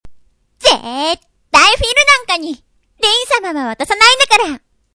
１４歳/女性
サンプルボイス